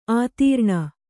♪ ātīrṇa